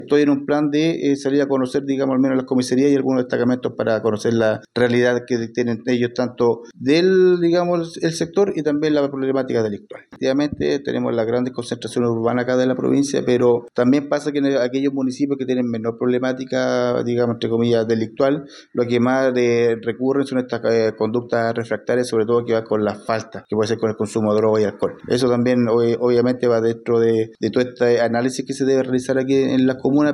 En entrevista con La Radio, la autoridad policial explicó que la provincia de Bío Bío presenta una diversidad de realidades, ya que, si bien existen ciudades con alta densidad poblacional como Los Ángeles, también hay comunas más apartadas que enfrentan problemáticas distintas.